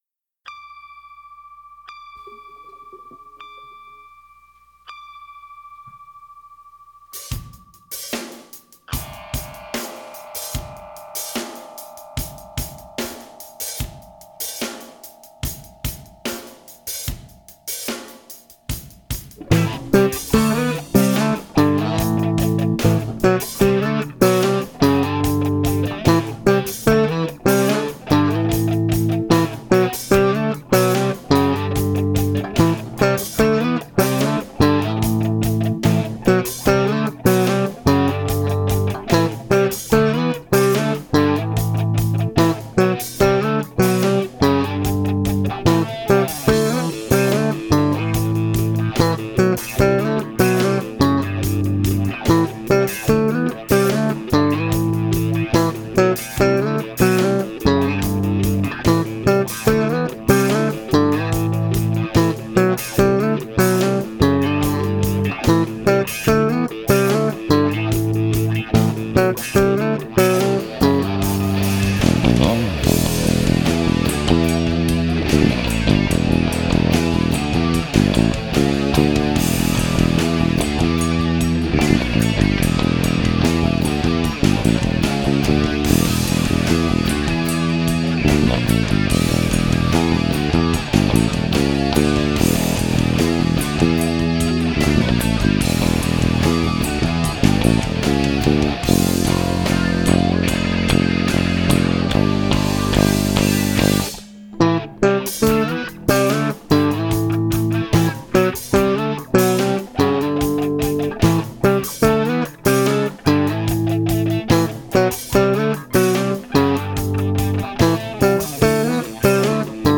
instrumental
Nur bass Tablatur